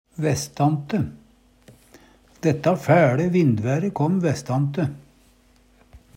vestante - Numedalsmål (en-US)